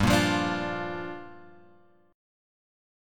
Gm7 chord {3 1 3 0 3 x} chord